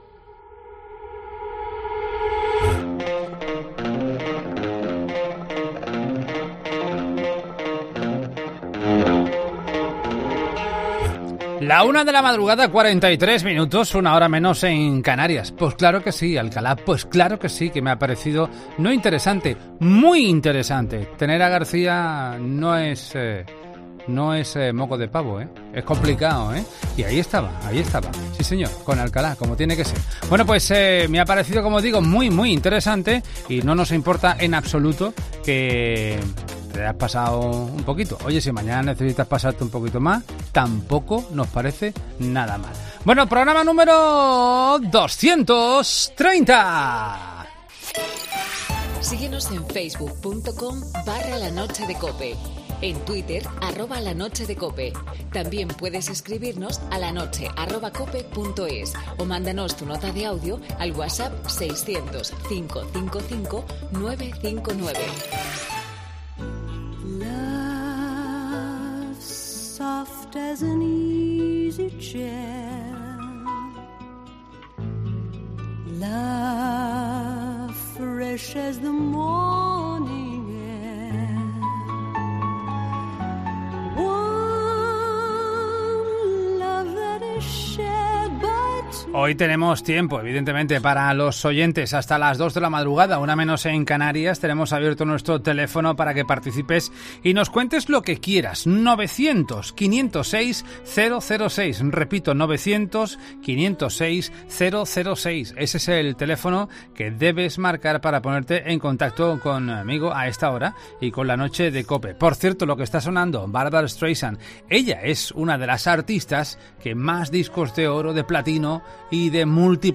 AUDIO: En el inicio de La Noche de Cope con Adolfo Arjona, oyentes en directo.